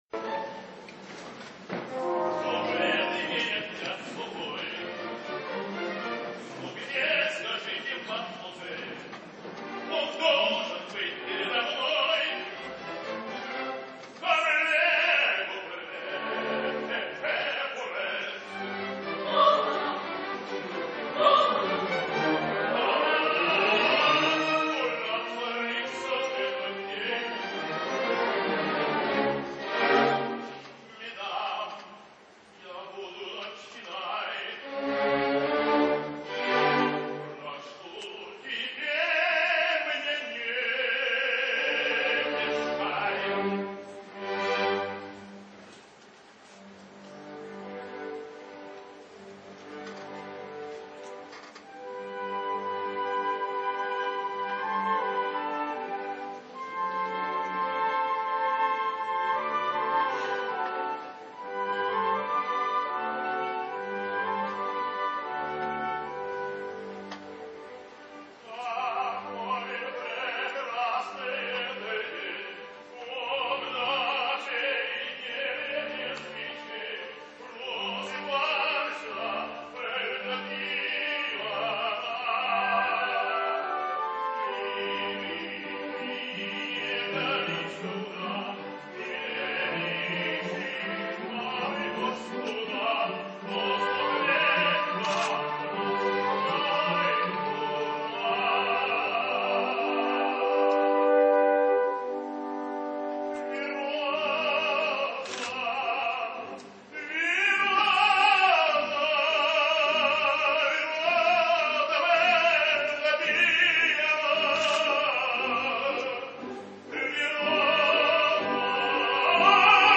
While Eastern Europe in general and Russia in particular have beyond doubt maintained quality in operatic singing for a much longer time than the rest of the world (and in some cases, they've maintained it even up to our days), it's the proof that there is no such thing as a Promised Land of opera, not even in Russia, if a positively horrible shouter and pusher like Osipov could become of the country's most famous tenors...
Vjacheslav Osipov singsEvgenij Onegin: